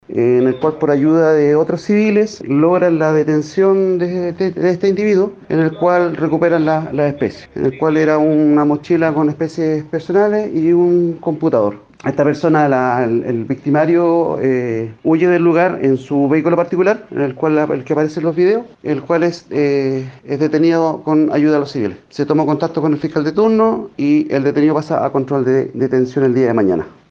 “Con ayuda de otros civiles, logran la detención de este individuo, en el cual recuperan las especies, el cual era una mochila con especies personales y un computador”, aseveró.